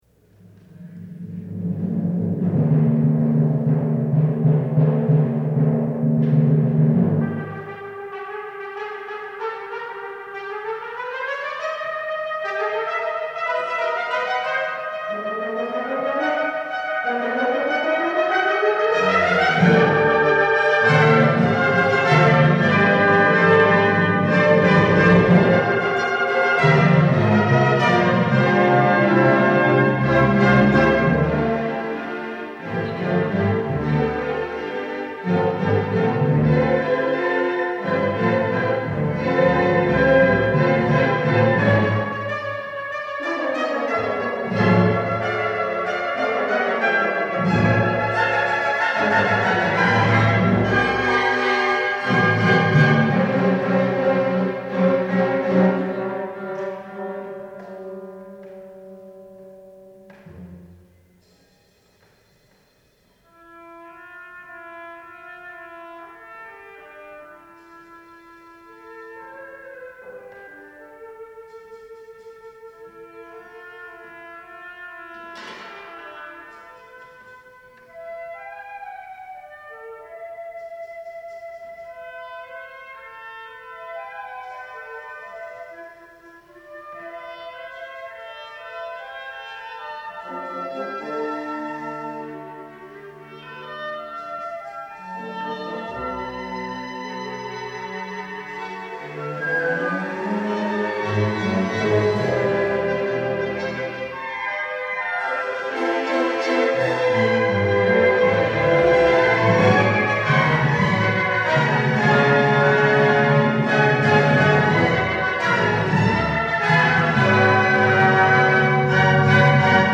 for Orchestra (1980)